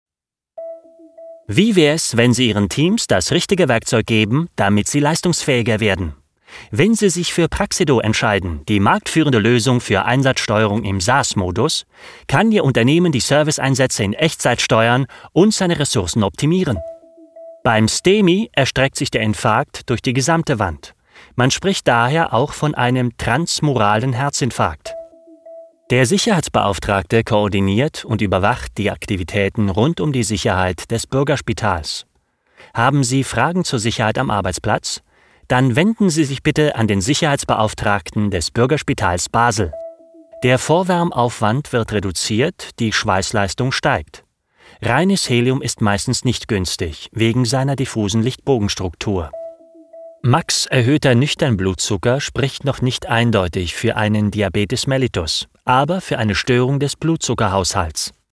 Souverän, Seriös, Selbstbewusst, Markant, Glaubwürdig, Vielseitig & Wandelbar, Sympathisch, mit Wiedererkennungswert
Kein Dialekt
Sprechprobe: eLearning (Muttersprache):
Believable, Versatile, Trusted, Characters, Young, Actor, Casual